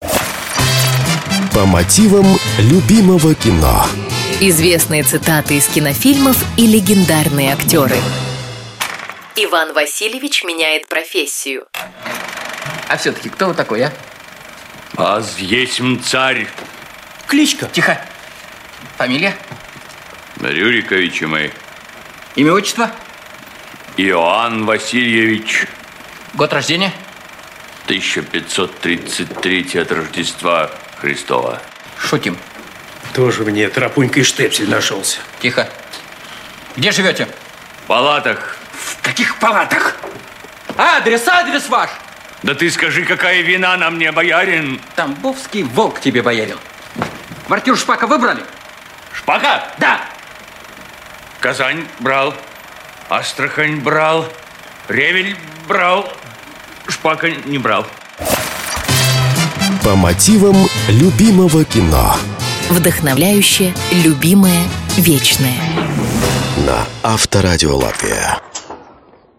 В этой программе вы услышите знаменитые цитаты из кинофильмов, озвученные голосами легендарных актеров.